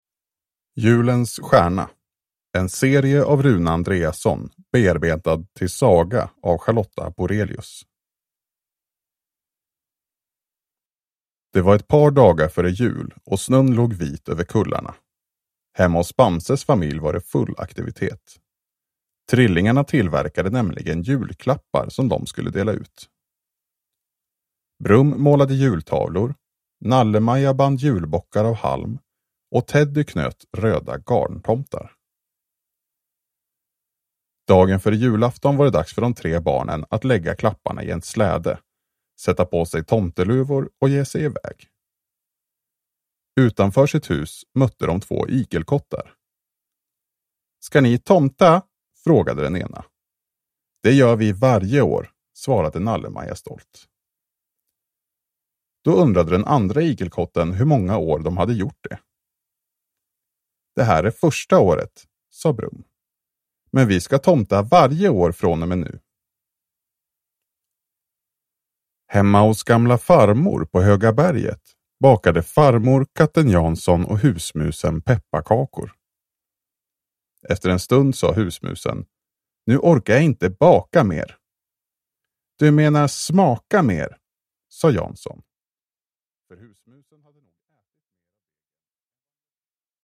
Bamse. Julens stjärna – Ljudbok